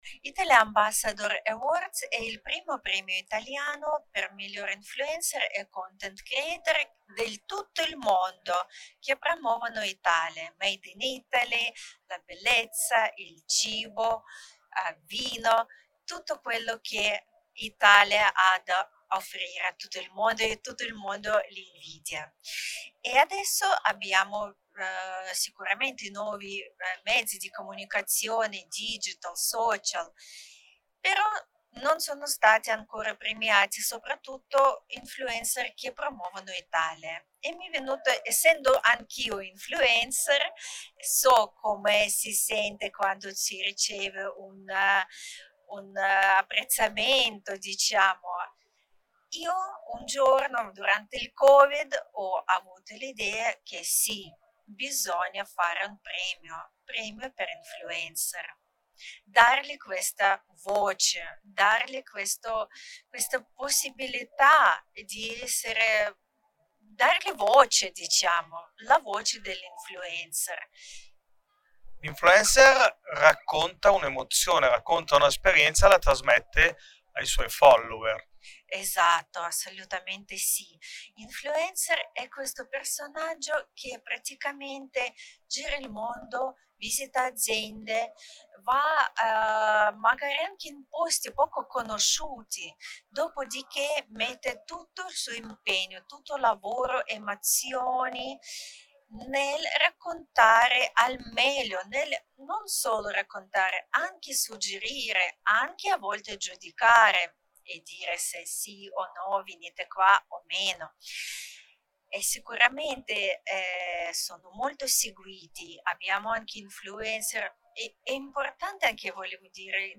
Home Magazine Interviste Italy Ambassador Awards